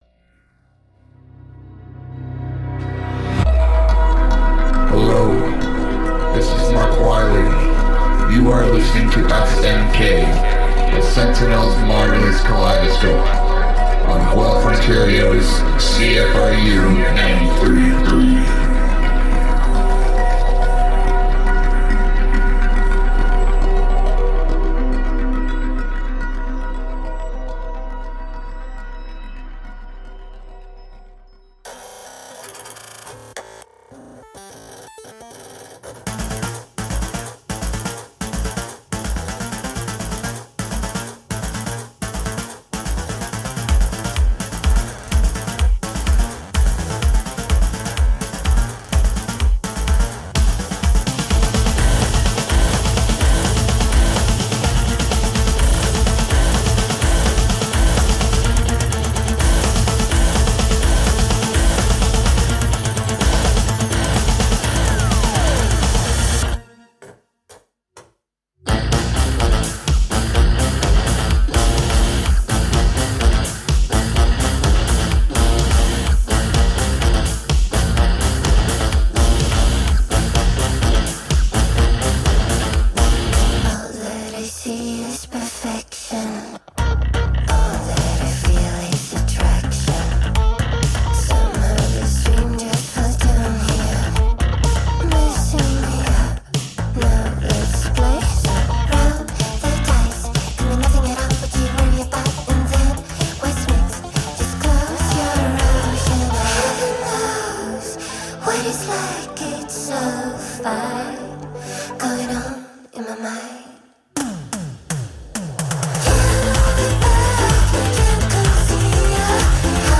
Looking inward and beyond at progressive, fusion and alternative sonic styles, near and far...